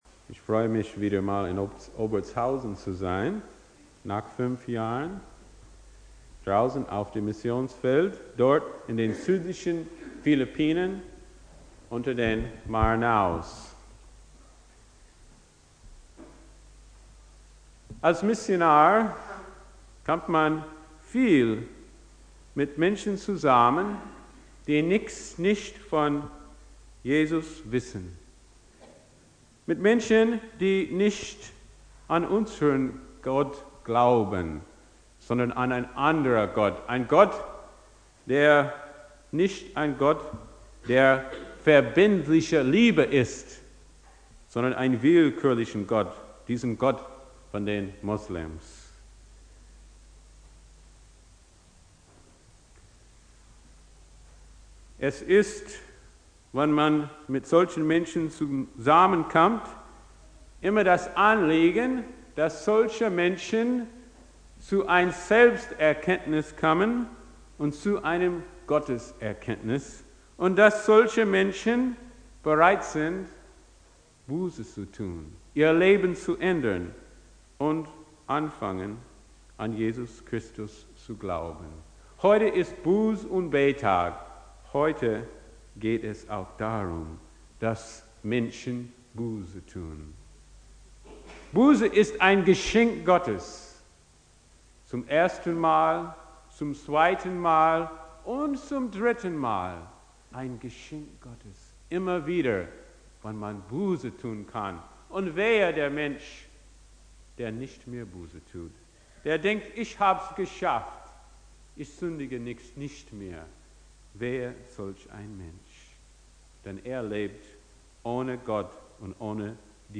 Predigt
Buß- und Bettag